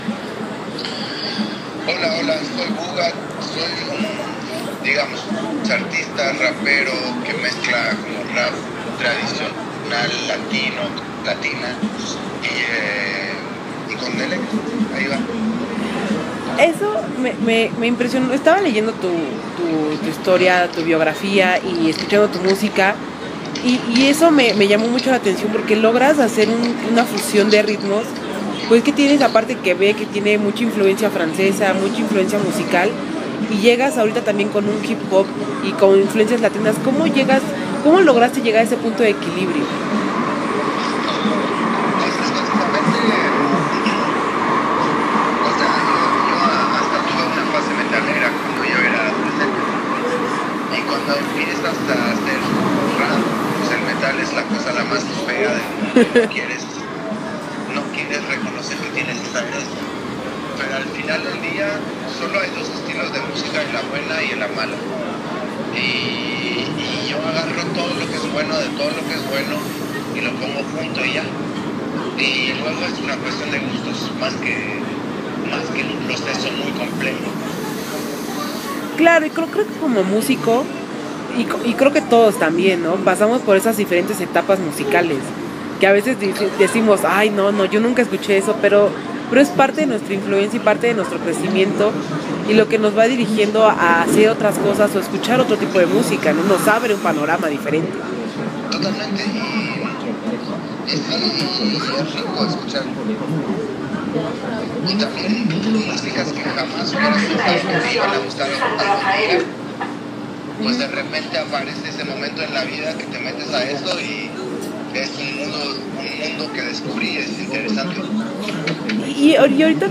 Aquí les dejamos la plática para que se vayan preparando porque la va a romper muy cabrón este año.